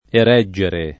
erigere [ er &J ere ] v.;